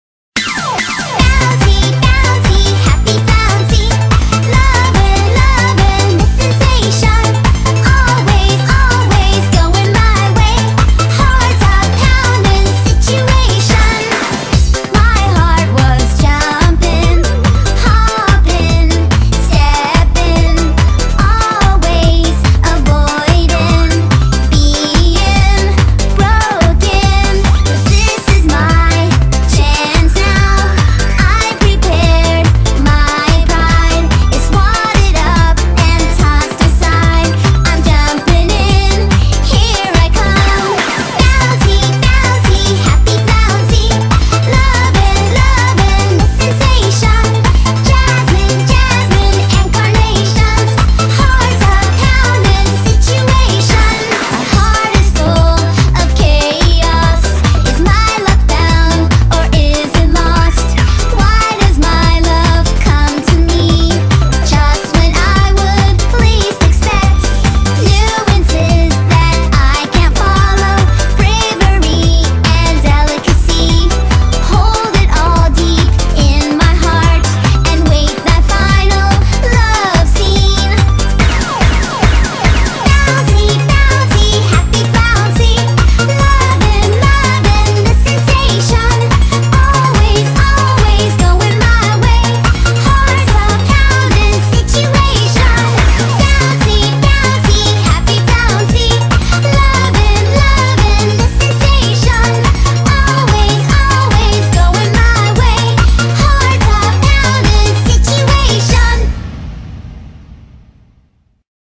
BPM144
Audio QualityCut From Video